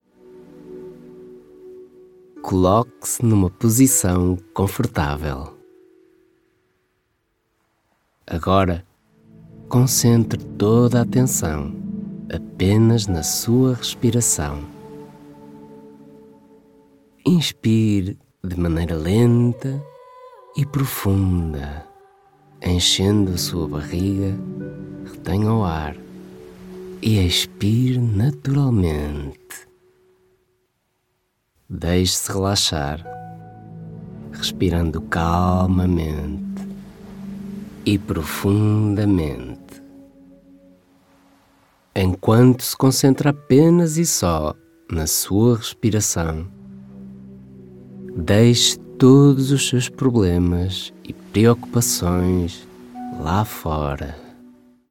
Erzählung
Tonhöhe